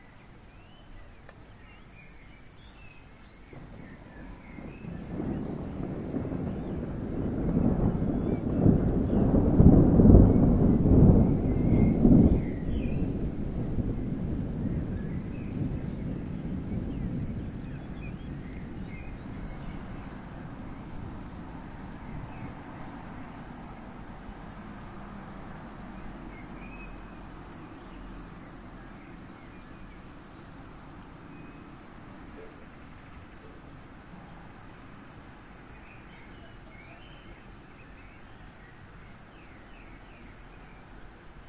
描述：标题：雷暴，鸟类，CarsPack：0002声音：018日期：〜06.2015录像机：奥林巴斯LS12 +挡风玻璃处理：放大特性：FLAC 44.1kHz，24位，立体声NER：~35dB 闪电距离约2公里。